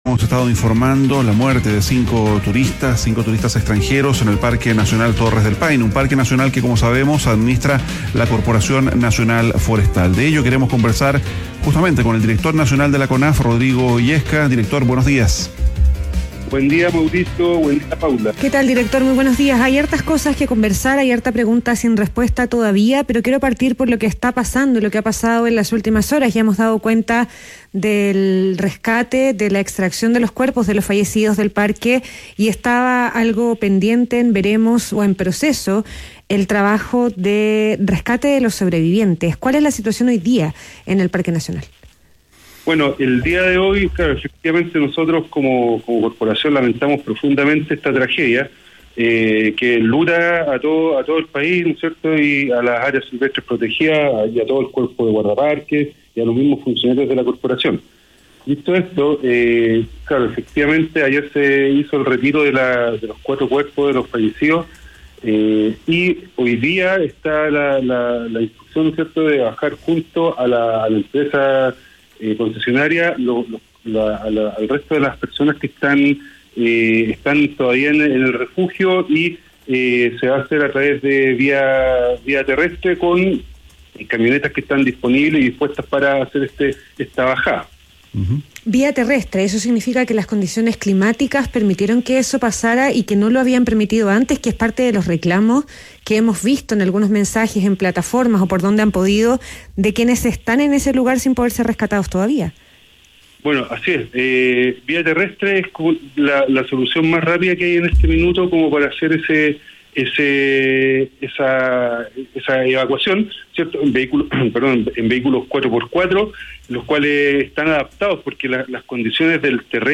ADN Hoy - Entrevista a Rodrigo Illesca, director ejecutivo de Conaf